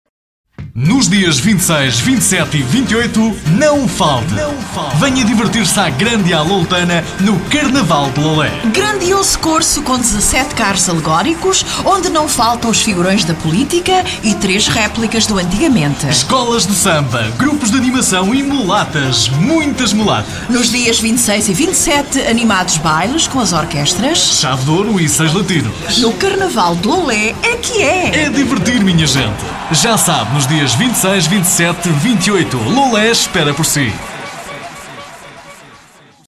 Desde sempre, os spots publicitários elaborados pelos Parodiantes comportam um formato de comunicação com sentido humorístico!
Carnaval de Loulé Spot Rádio Novos Parodiantes